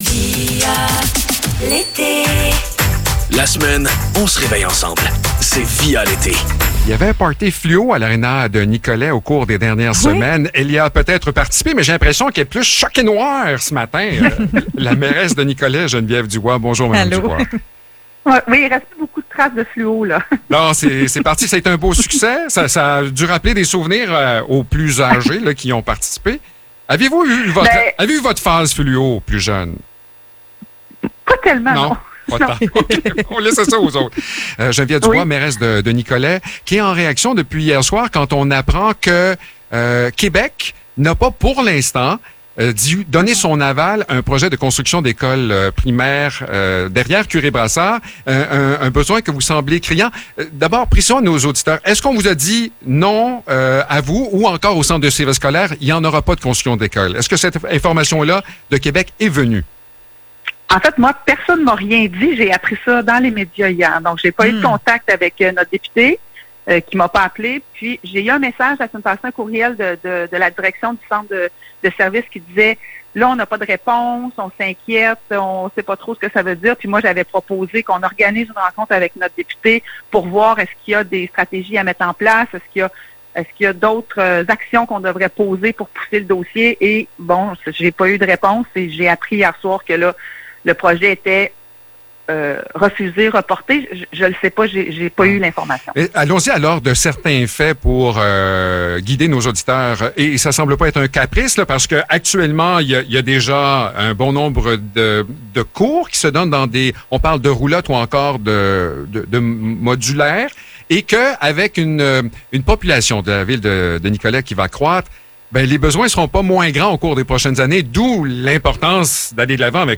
Geneviève Dubois, mairesse de Nicolet, s’exprime sur le sujet du refus du gouvernement de construite une nouvelle école à Nicolet.